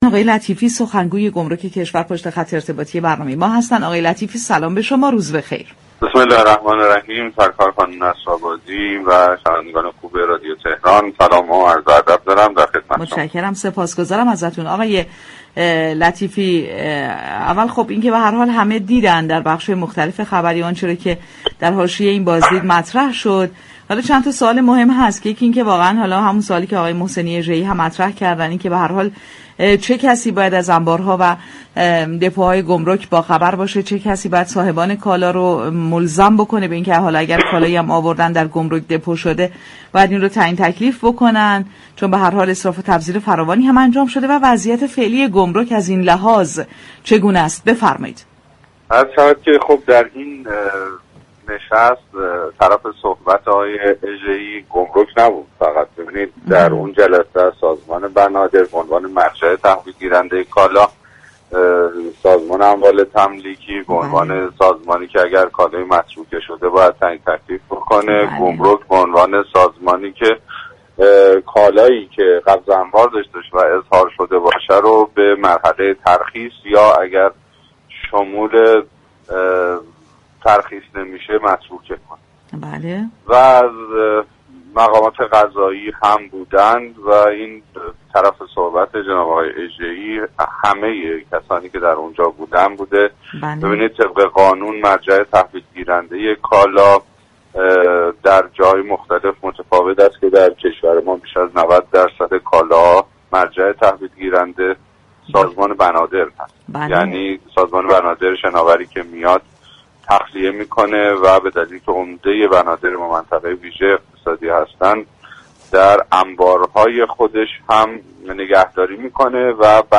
در گفت‌و‌گو با بازار تهران رادیو تهران